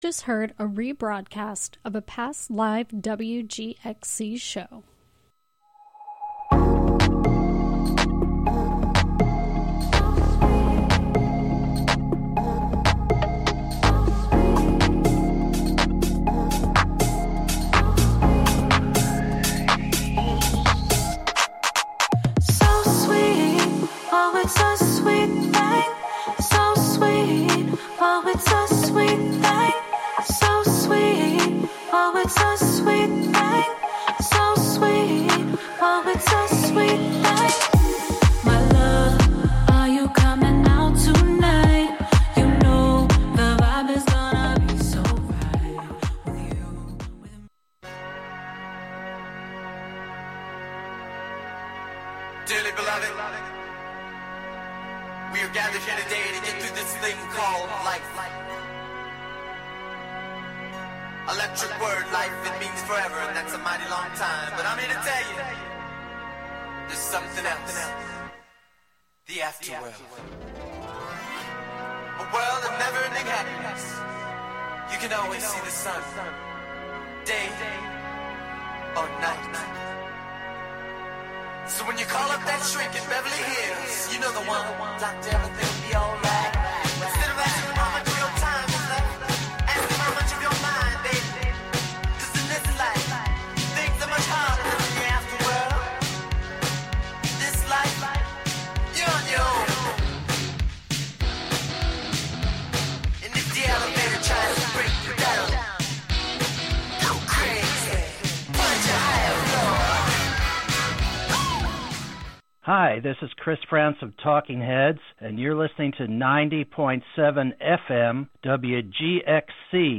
9am This music mix show carries the message